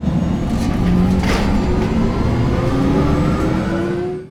engine_start_003.wav